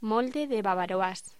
Locución: Molde de bavaroise
voz